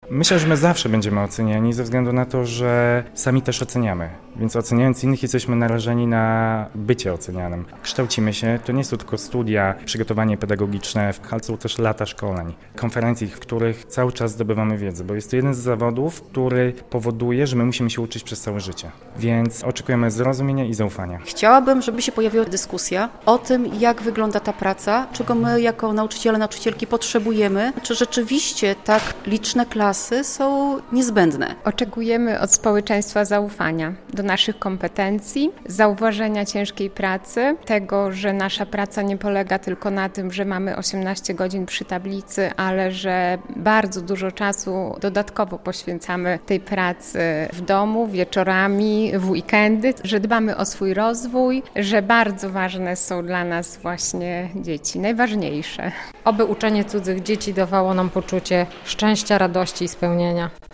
W Dniu Edukacji Narodowej zapytaliśmy nauczycieli białostockich szkół, jakie mają oczekiwania wobec społeczeństwa.